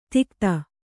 ♪ tikta